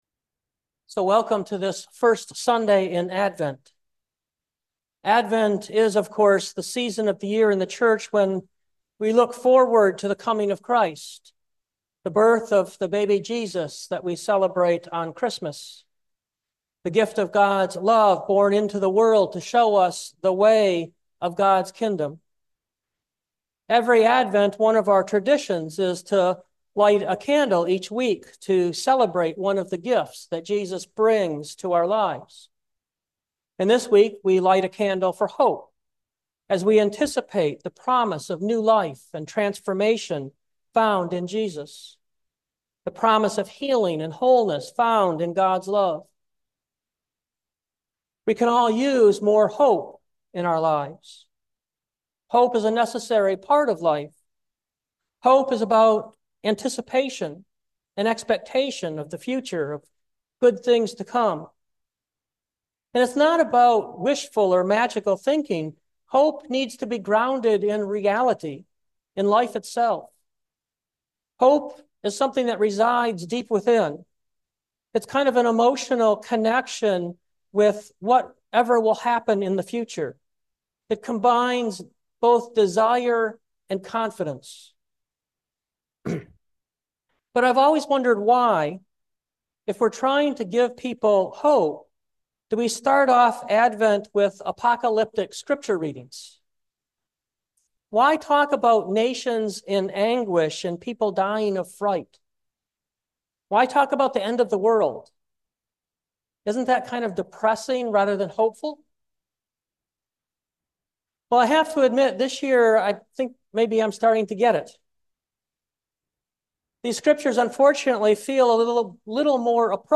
2024 Stand Up in Hope Preacher